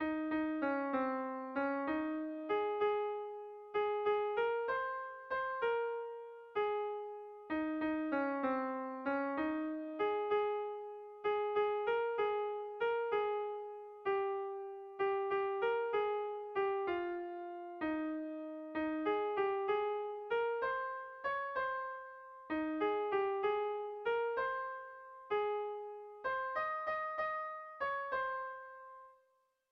Dantzakoa
Zortziko txikia (hg) / Lau puntuko txikia (ip)